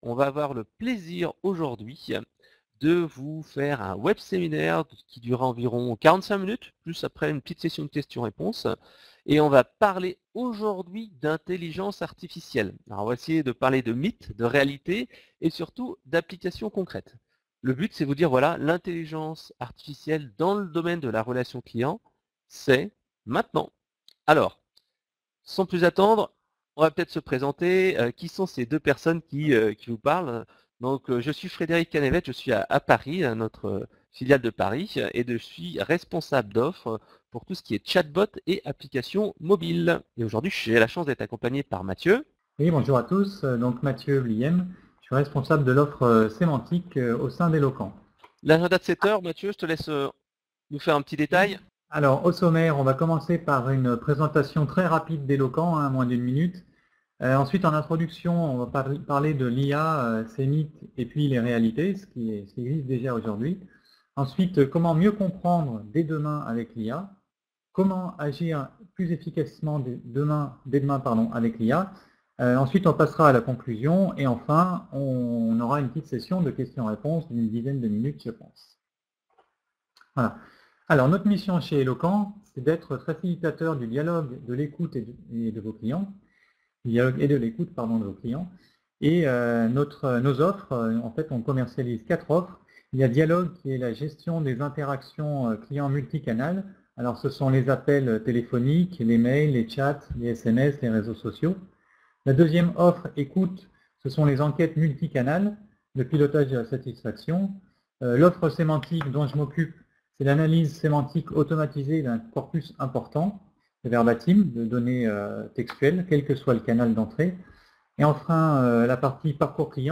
Voici le Replay du Web Séminaire : Intelligence Artificielle et Relation Client, c'est Maintenant